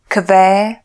Pronounciation
Slovak voice announciation